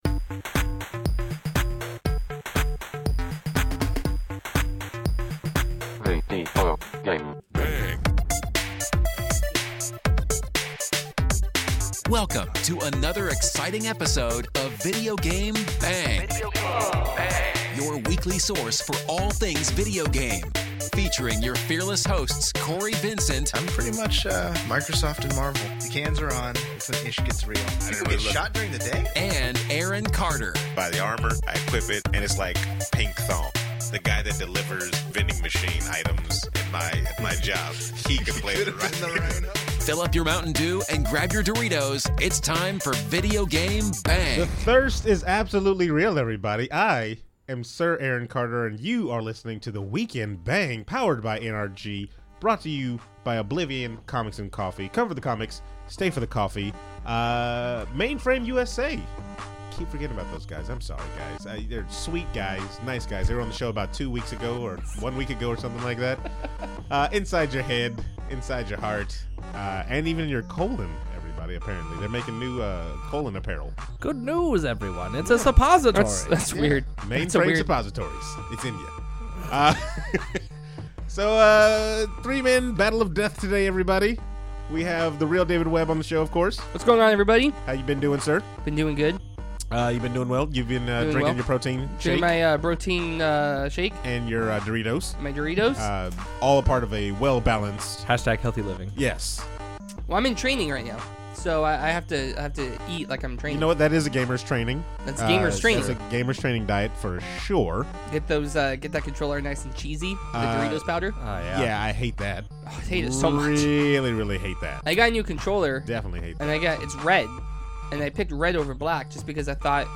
The Weekend BANG! is back after a small break and we bring a three man roster.